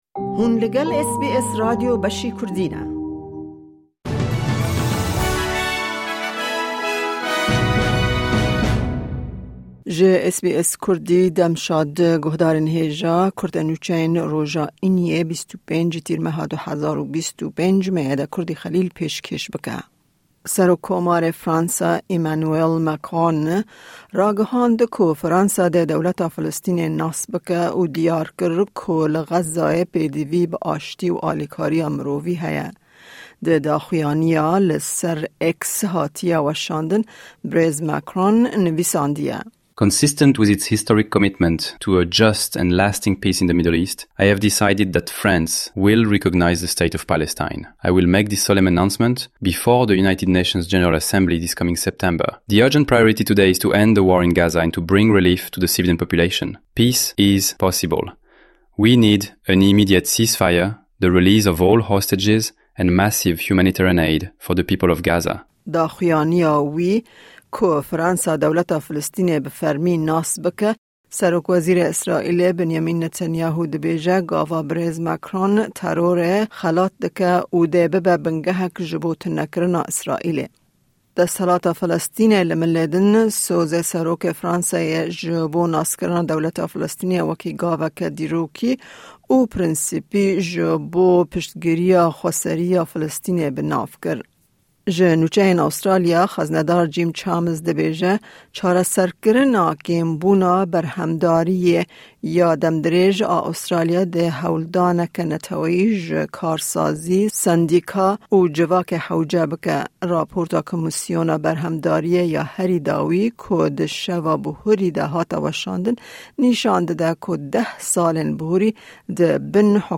Kurte Nûçeyên roja Înî 25î Tîrmeha 2025